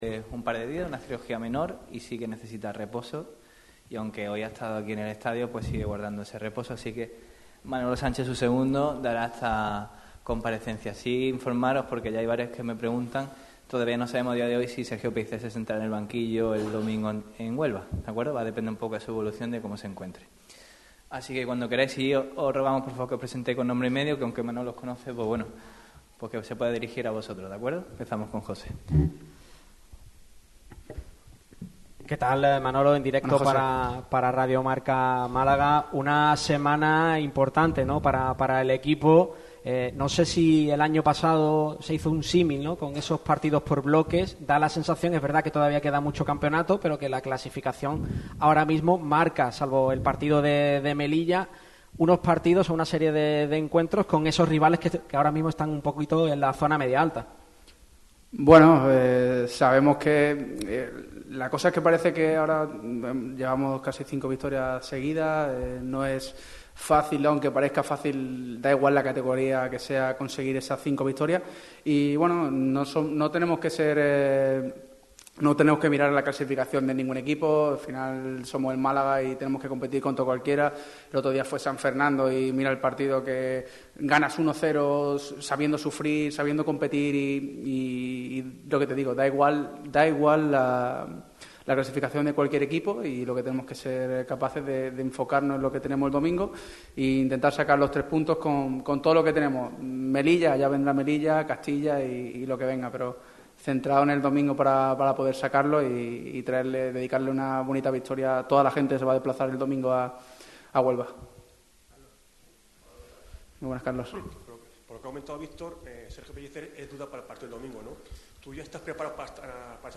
El motivo de la rueda de prensa es la previa del duelo que enfrentará a los boquerones contra el Recreativo de Huelva el domingo a las 20:00 horas.